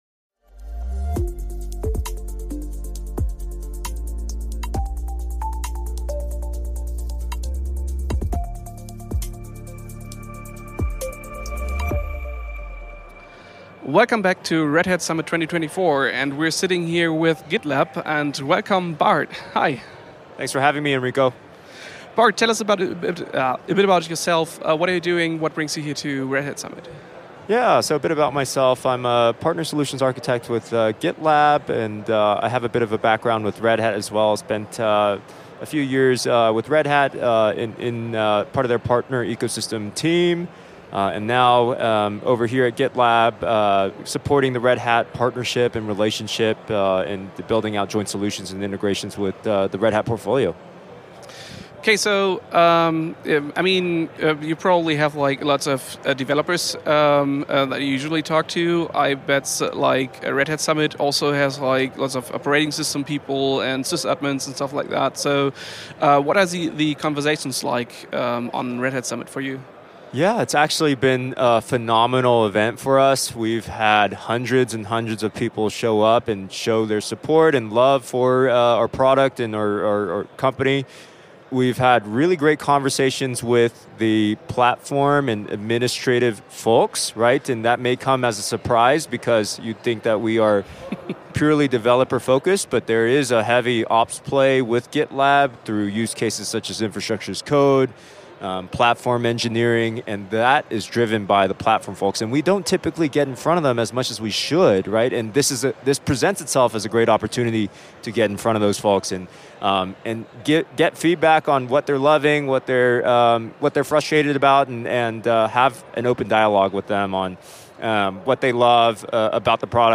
Beschreibung vor 1 Jahr Ein letztes Mal von der Red Hat Summit in Denver.